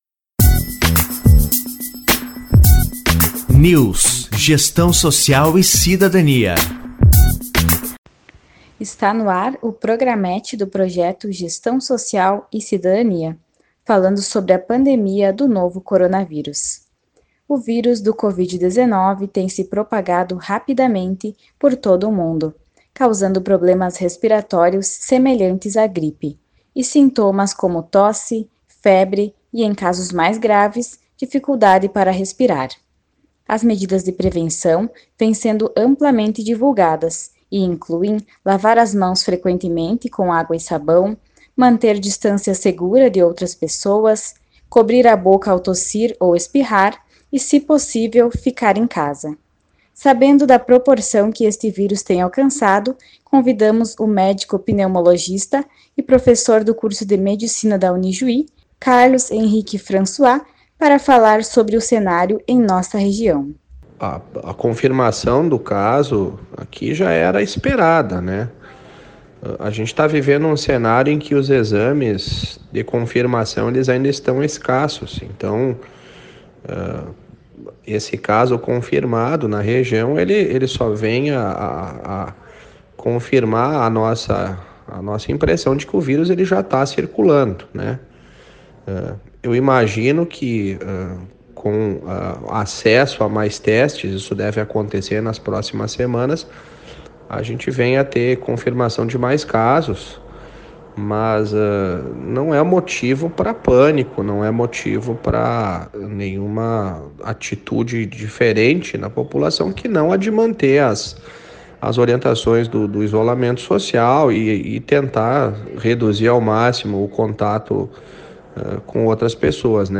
Entrevistado